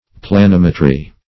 Planimetry \Pla*nim"e*try\, n. [Cf. F. planim['e]trie.]